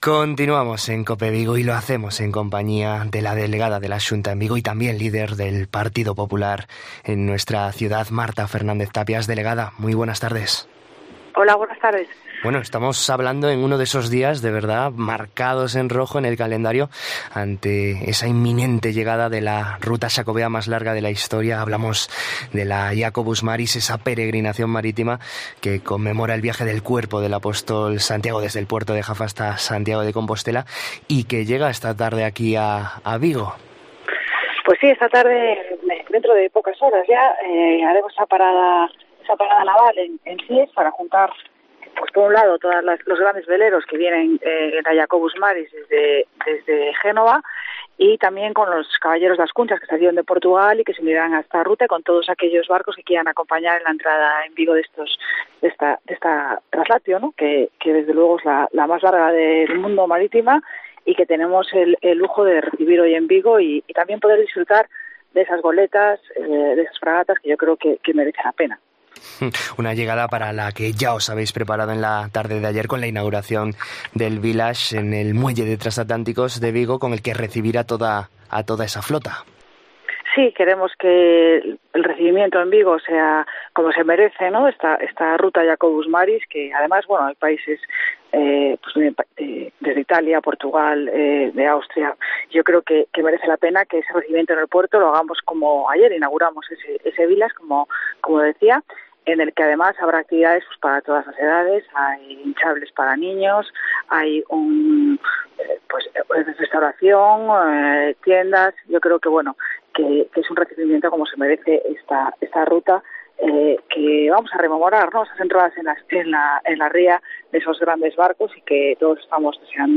En COPE Vigo hablamos con la delegada de la Xunta en Vigo y líder del PP de la ciudad